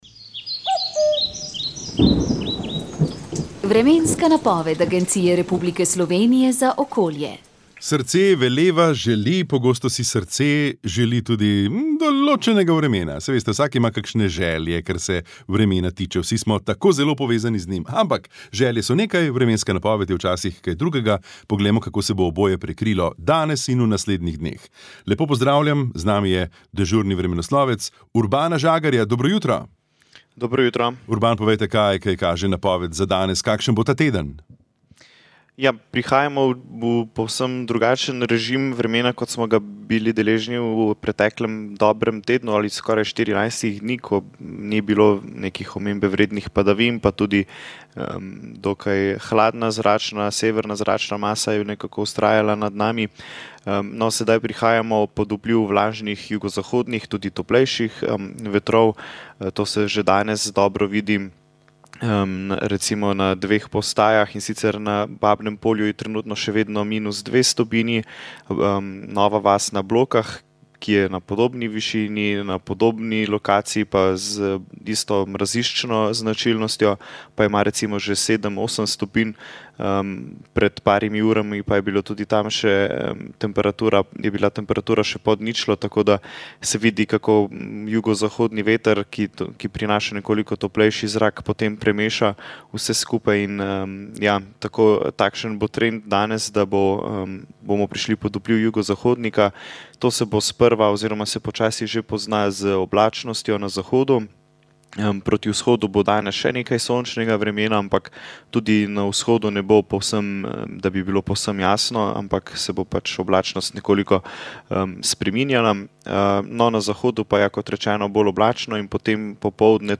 Pogovor s predsednikom Republike Slovenije Borutom Pahorjem